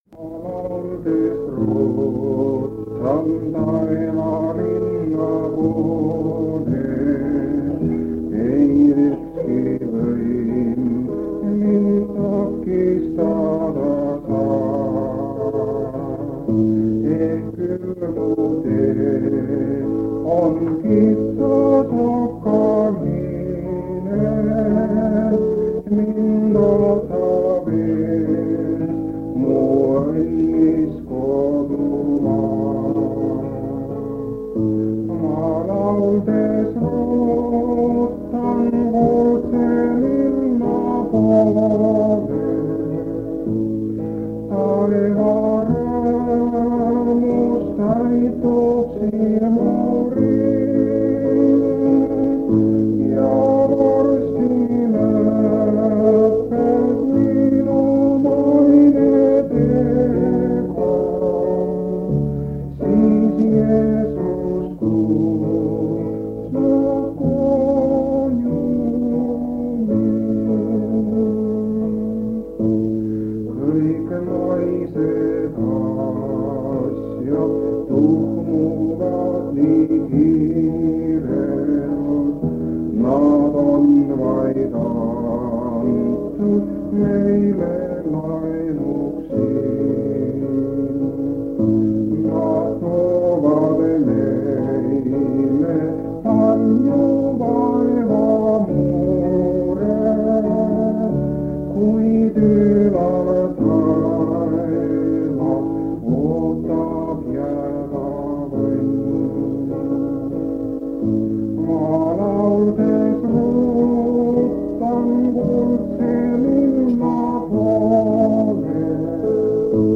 On 1977 aasta kevad. Paide adventkirikus toimub
Täpsemaid kuupäevi pole teada ning jagasin lintmaki lintidele talletatu kuueks päevaks.